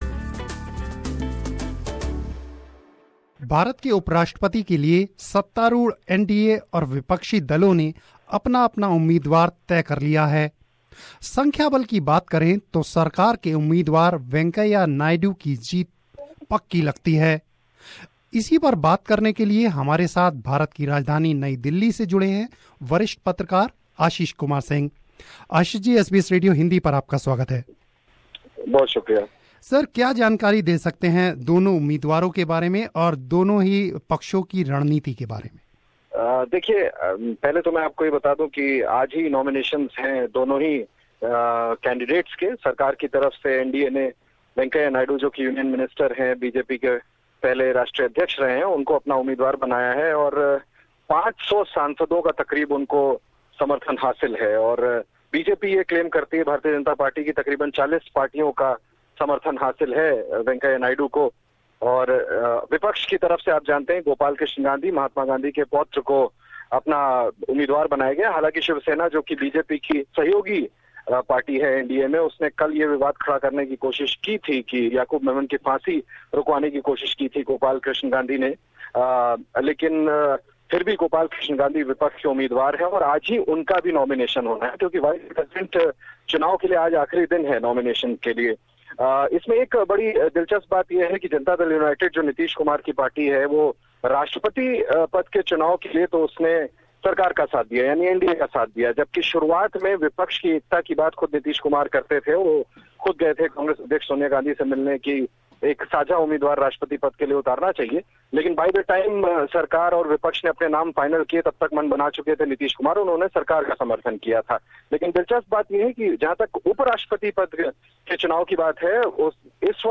talked to senior Journalist